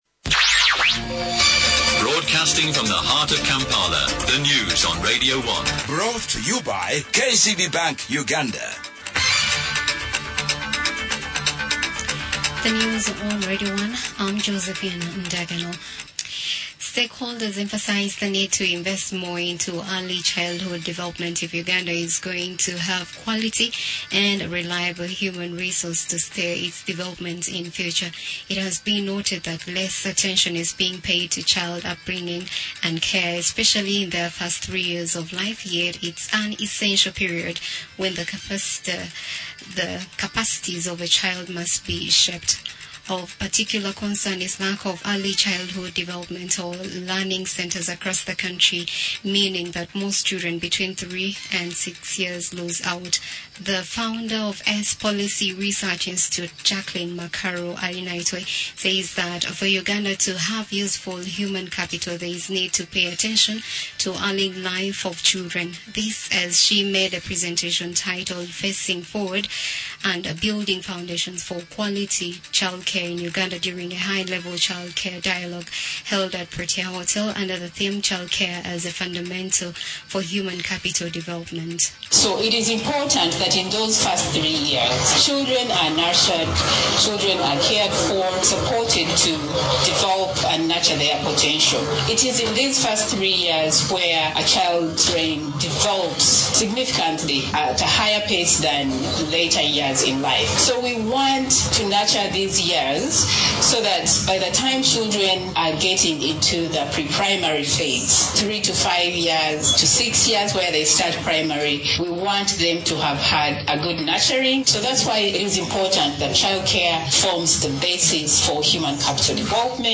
Audio News
ACE-Radio-One-News-English-.mp3